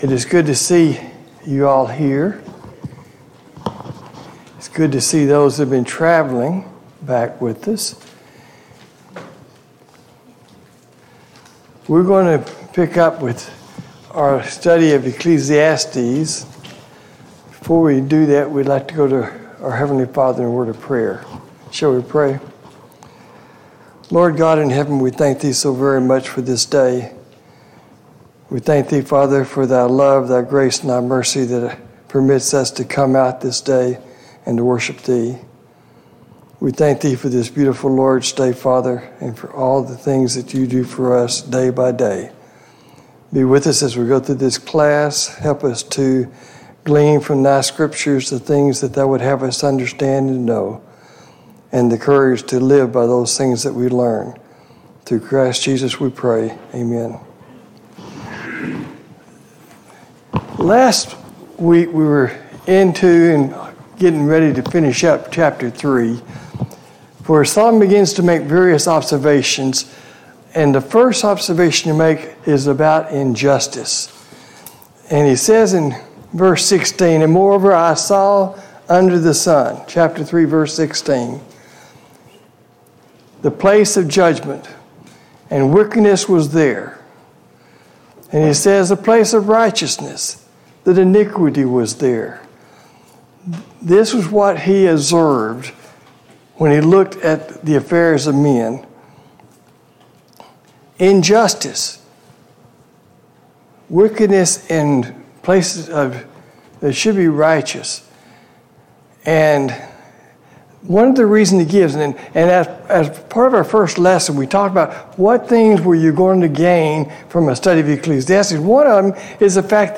A Study of Ecclesiastes Service Type: Sunday Morning Bible Class Topics